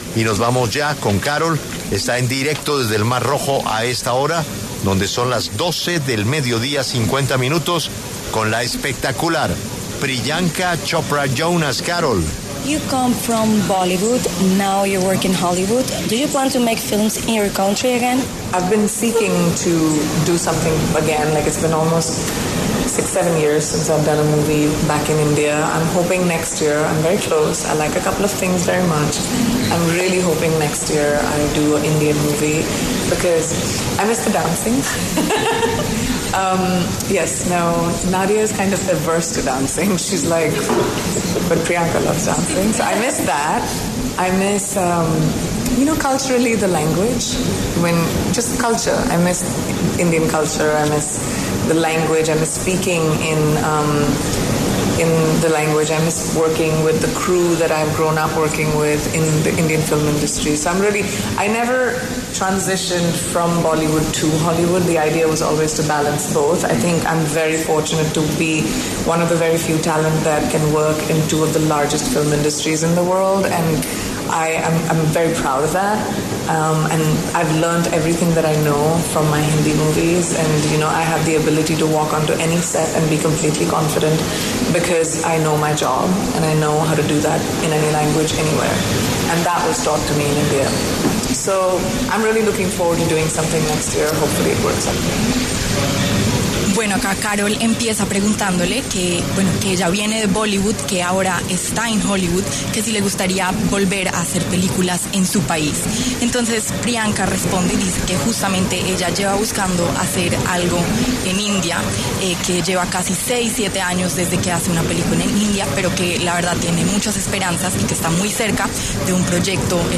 Priyanka Chopra Jonas, actriz y productora de cine india, conversó con La W, con Julio Sánchez Cristo, desde el Festival Internacional de Cine del Mar Rojo sobre el proceso que ha tenido su carrera al pasar de Bollywood a Hollywood.